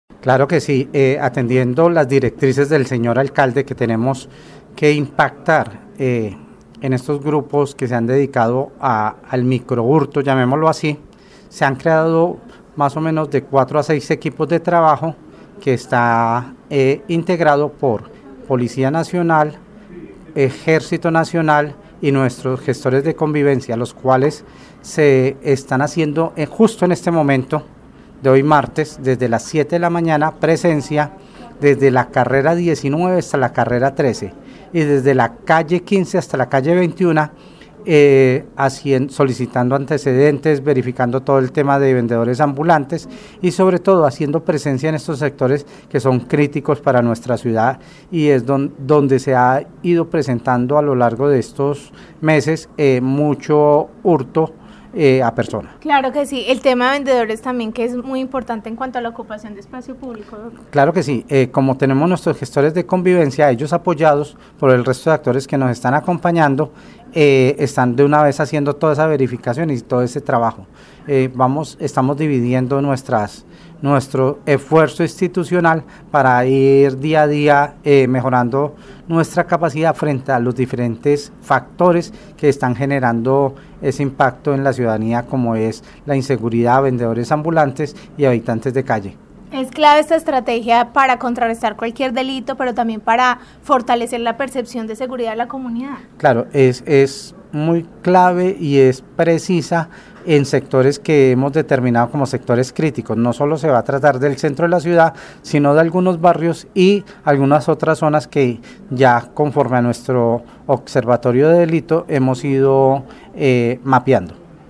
Secretario de Gobierno de Armenia
El secretario de Gobierno, Carlos Arturo Ramírez aseguró que buscan contrarrestar delitos como el hurto por eso han conformado seis equipos de trabajo que harán todo un recorrido por el centro de la ciudad y algunos barrios.